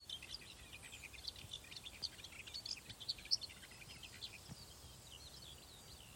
Klusais ķauķis, Iduna caligata
Administratīvā teritorijaAlūksnes novads
СтатусПоёт